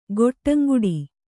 ♪ goṭṭaŋguḍi